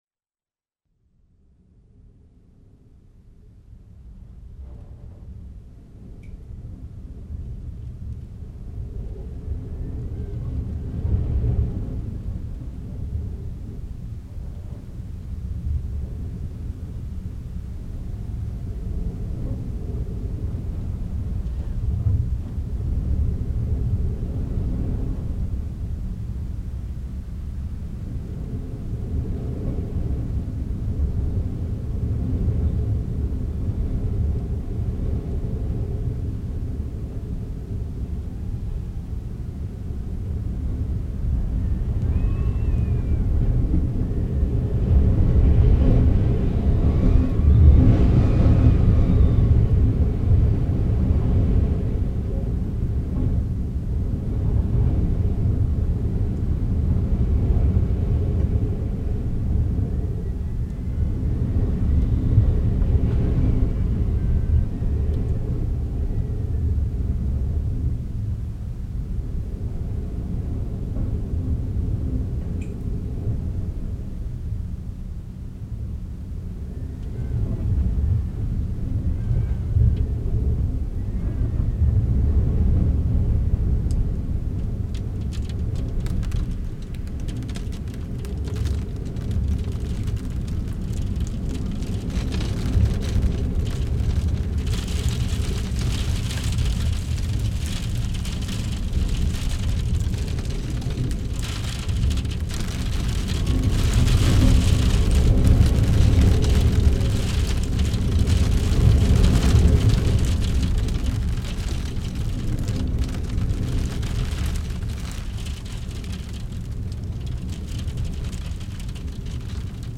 improvised and contemporary music